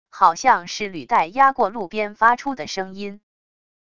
好像是履带压过路边发出的声音wav音频